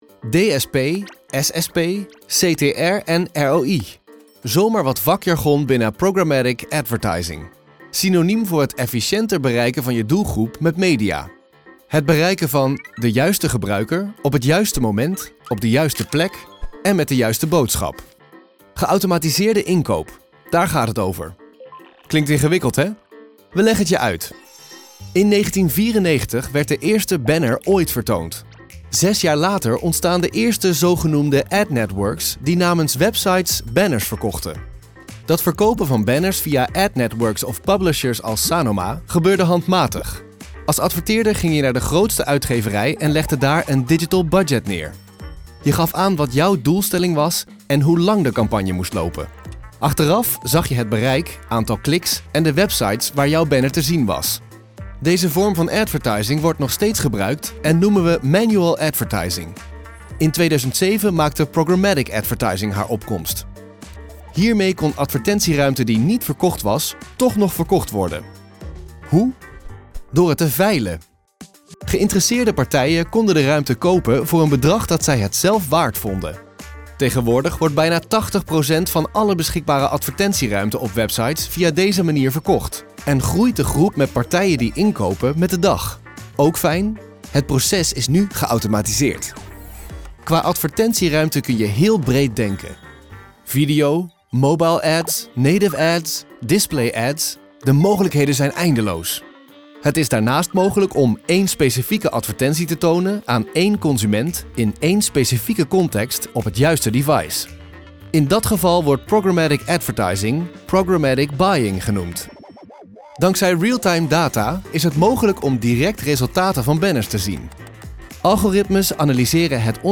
Kommerziell, Vielseitig, Zuverlässig, Freundlich, Warm
Erklärvideo
His voice is often described as warm and approachable. It is not a standard voice-over sound, but rather a sound that stands out without dominating. Think of a fresh, mature voice with that typical millennial sound: clear, accessible and fresh.